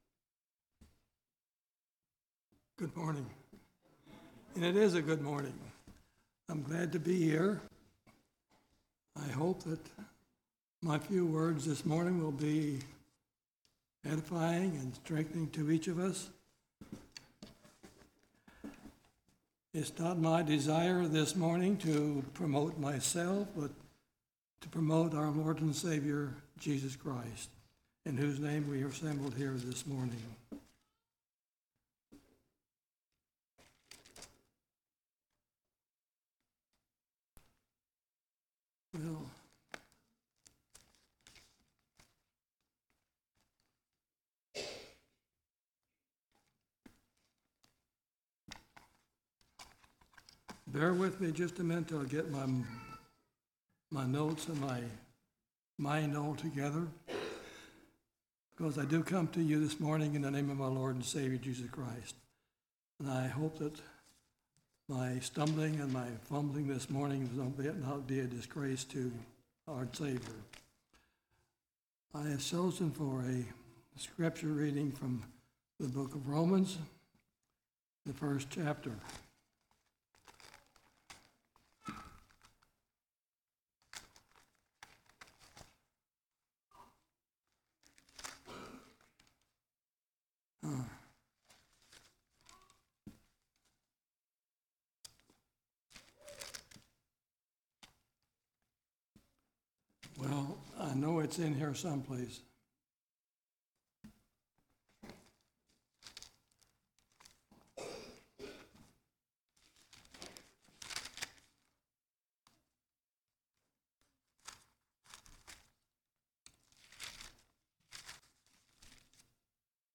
1/29/2023 Location: Temple Lot Local Event